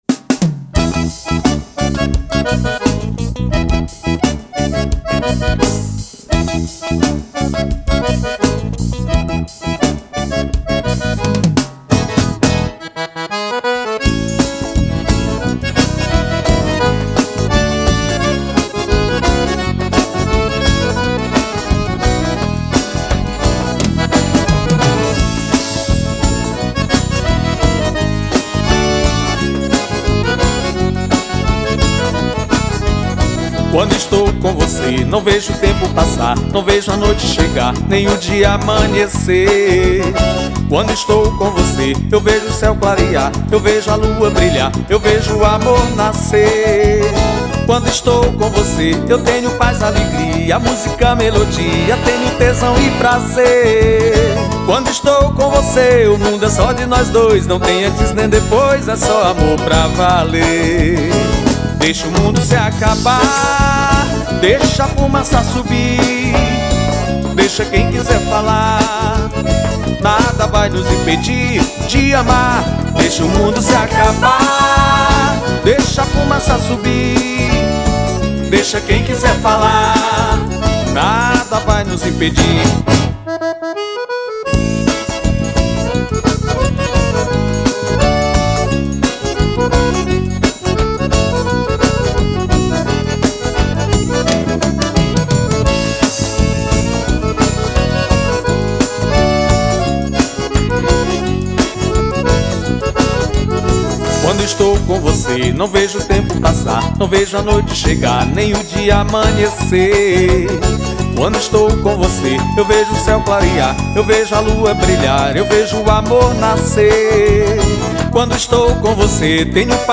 2536   03:08:00   Faixa: 15    Baião